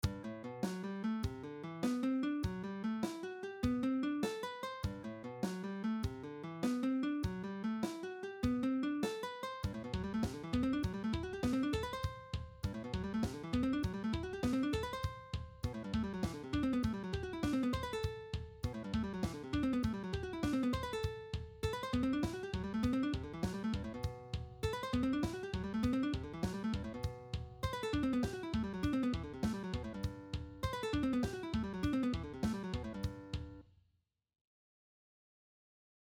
A big lick in G major with lots of string skipping.
G-Major-String-Skipping-Exercise.mp3